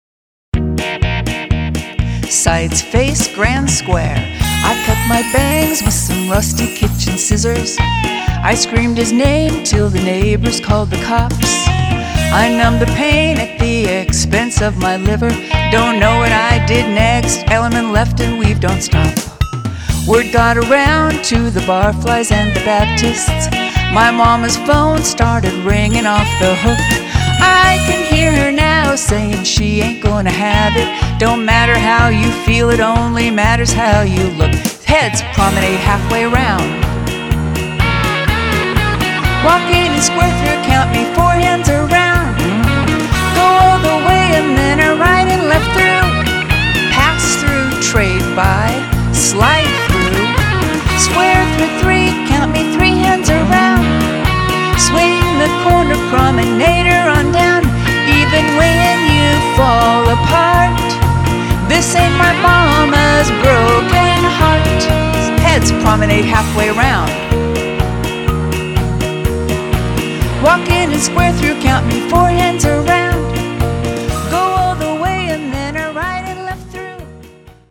Mainstream